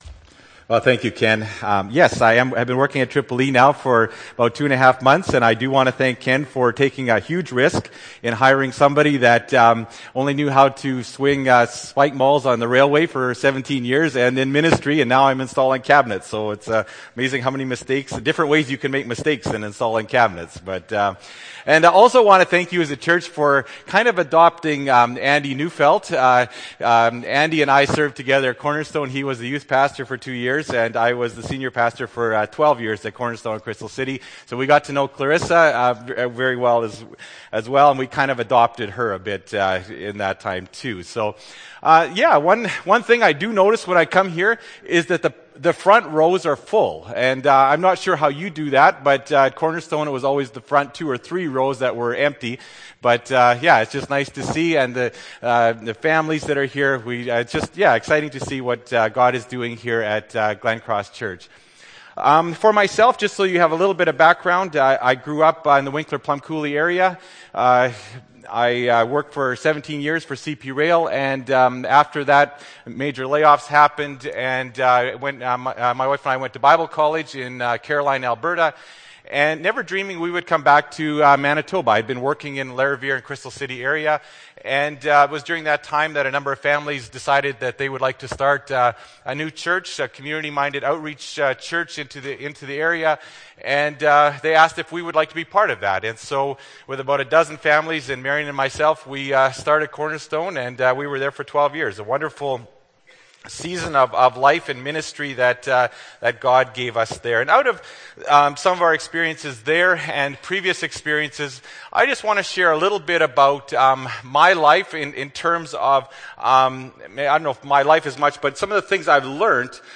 Nov. 13, 2011 – Sermon